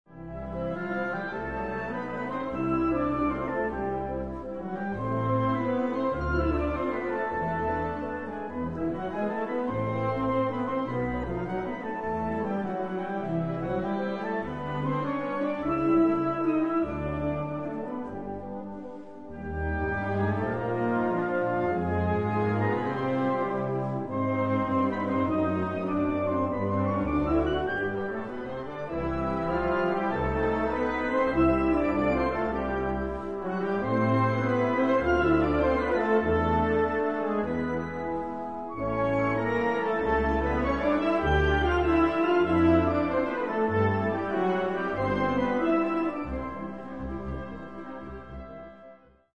pas-redoublé